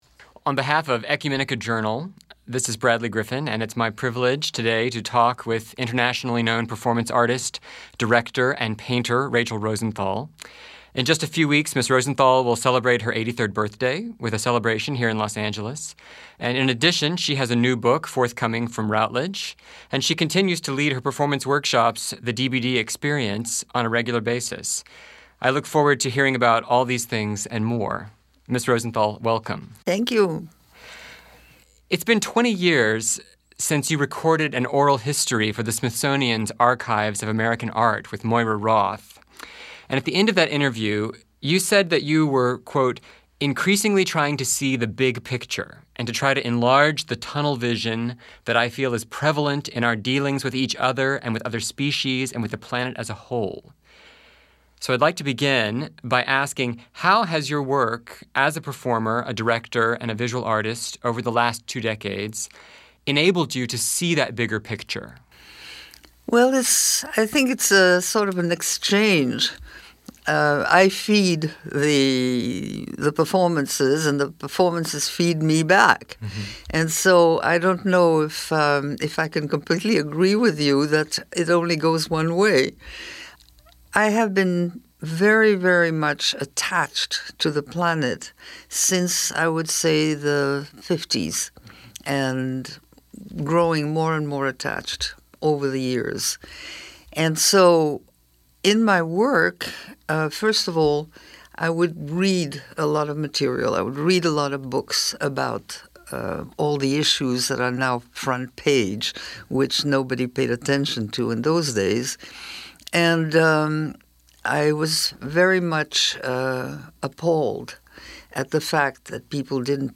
Interview with Rachel Rosenthal (Audio)